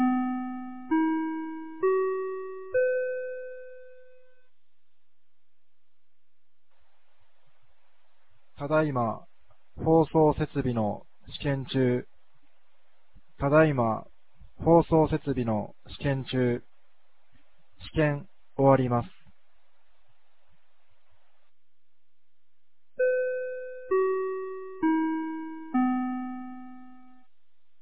2025年10月11日 16時02分に、由良町から全地区へ放送がありました。